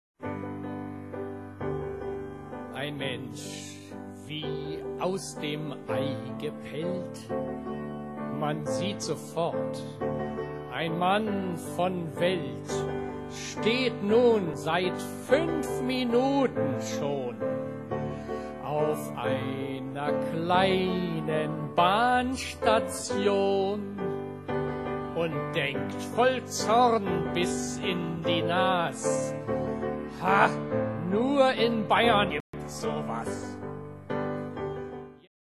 Heiteres Soloprogramm am Klavier